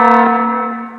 alarmbuzz.ogg